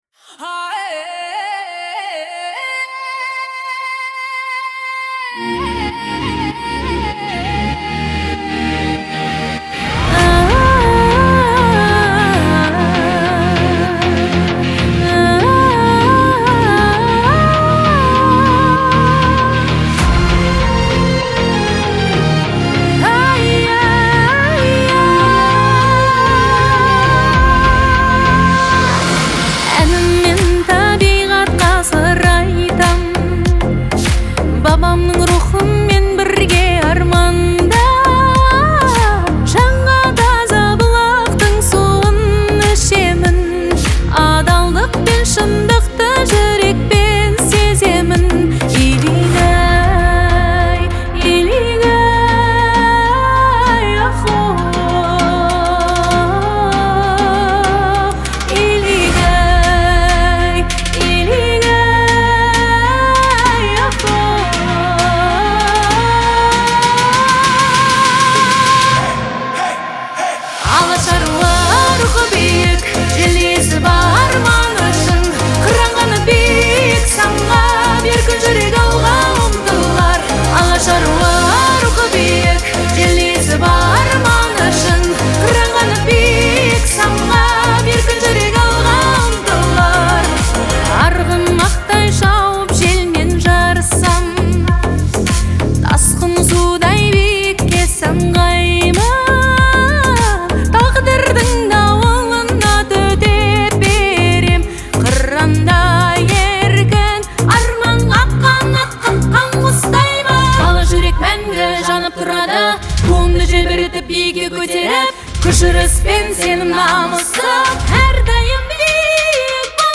Трек размещён в разделе Казахская музыка.